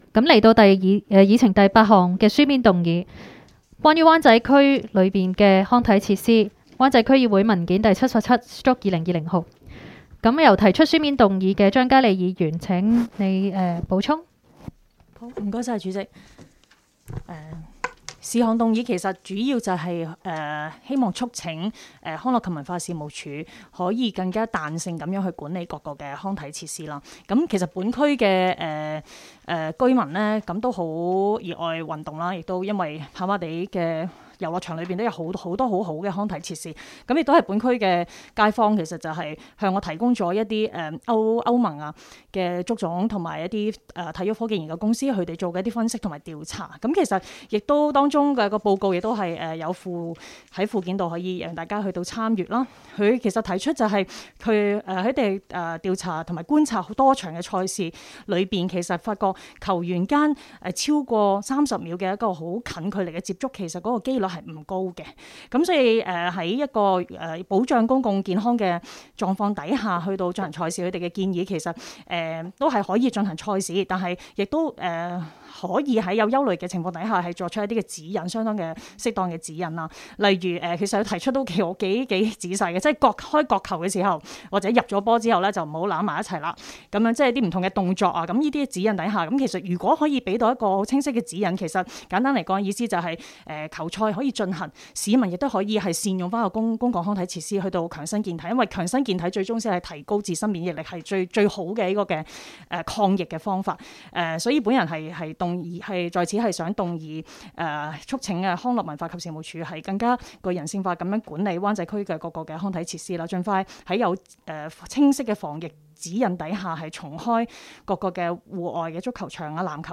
湾仔区议会会议室